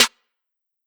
Waka Snare 1 (3).wav